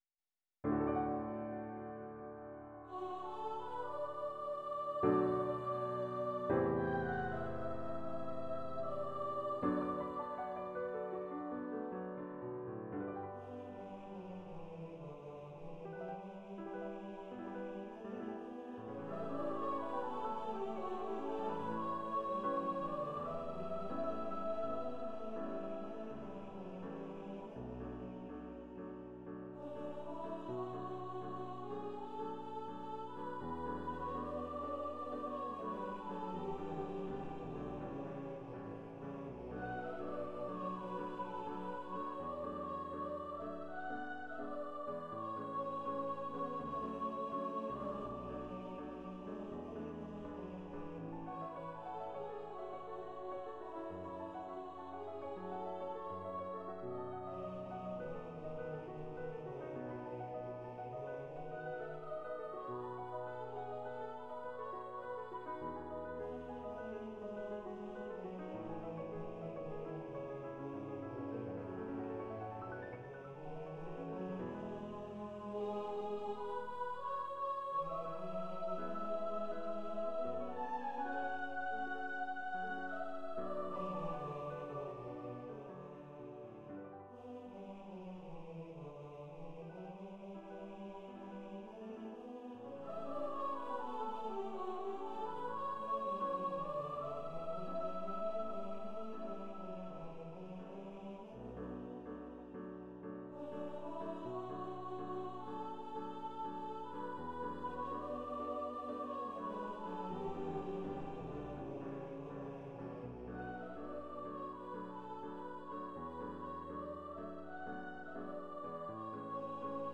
Soprano, Baritone Voices and Piano
Composer's Demo